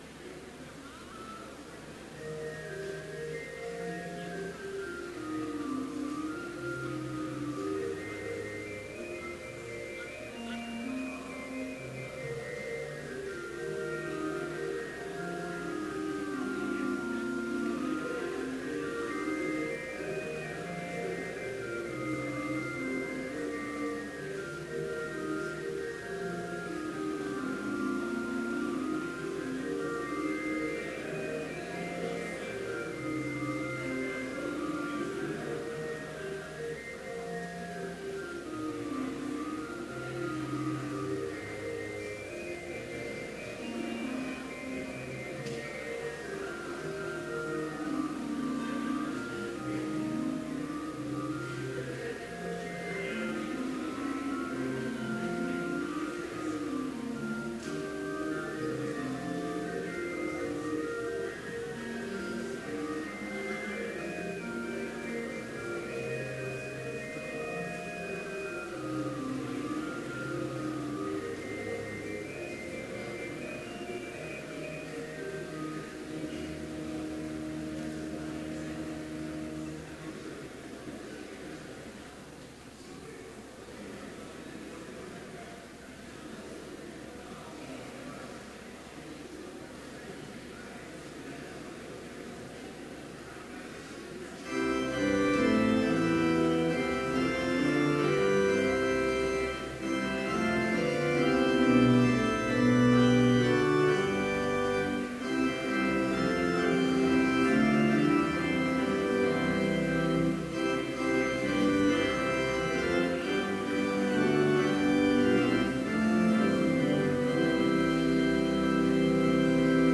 Complete service audio for Chapel - April 13, 2018
Watch Listen Complete Service Audio file: Complete Service Sermon Only Audio file: Sermon Only Order of Service Prelude Hymn 366, vv. 1-7, Ye Sons and Daughters of the King Reading: I John 5:4 Devotion Prayer Hymn 366, vv. 8-10, Blessed are they…